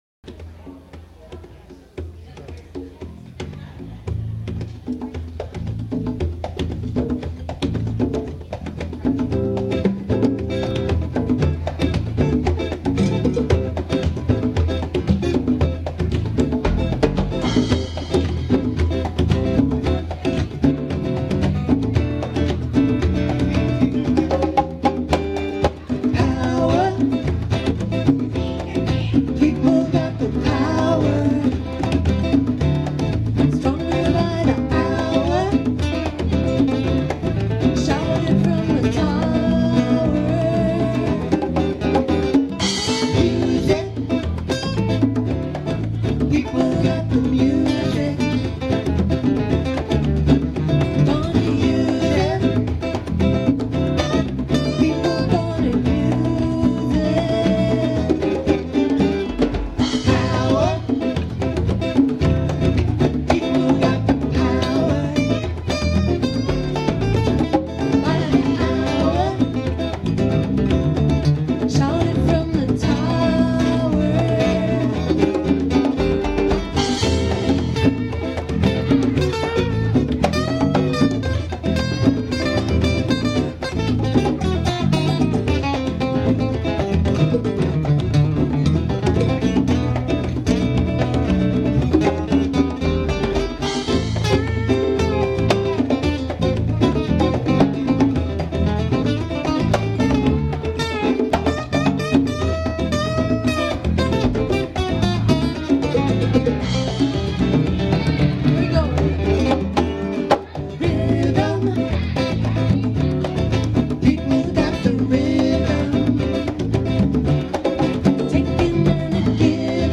band 10/21/07